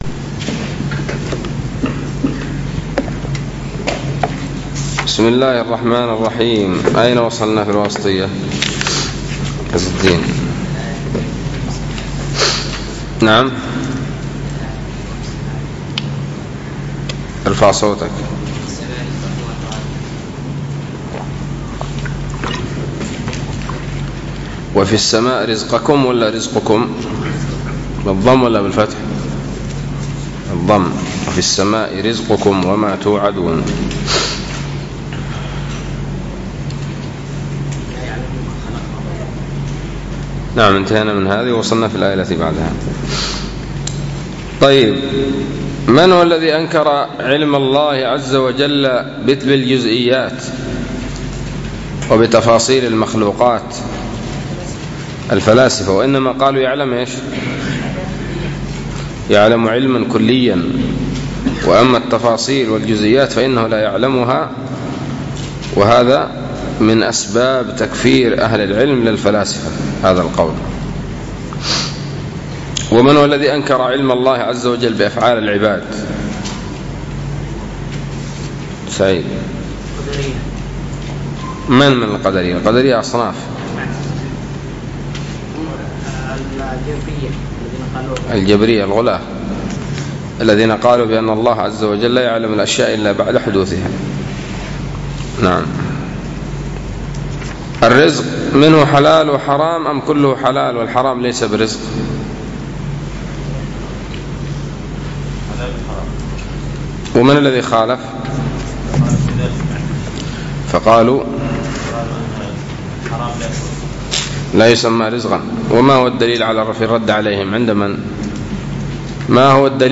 الدرس الثالث والأربعون من شرح العقيدة الواسطية